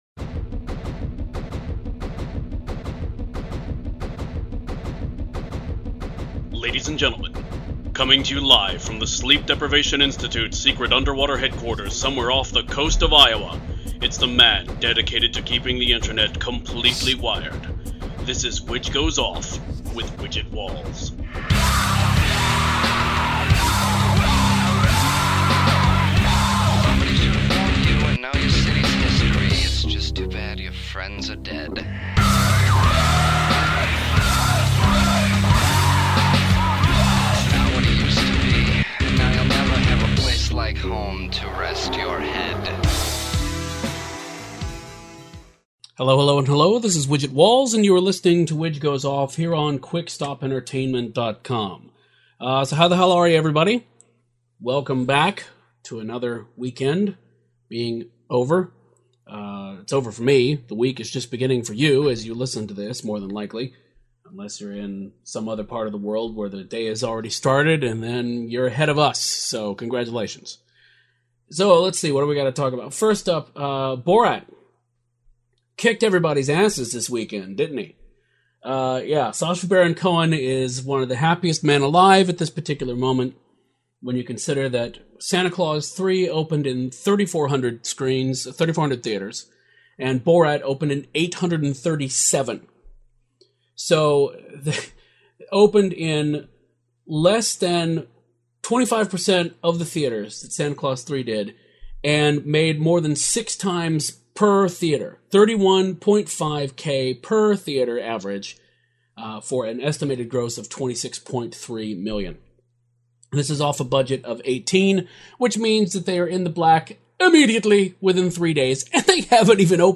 [CONTENT WARNING] This podcast contains foul language and MSG.